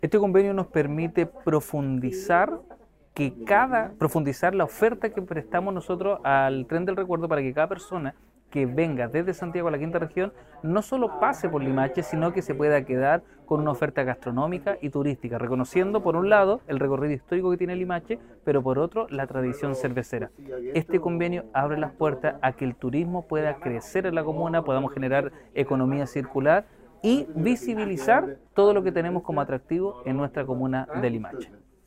El alcalde de Limache, Luciano Valenzuela, destacó la importancia de esta alianza: